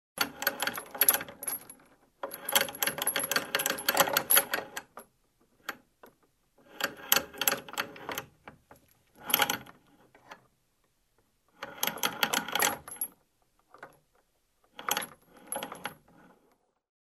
Звуки колодца
Звон цепочки в глубине колодца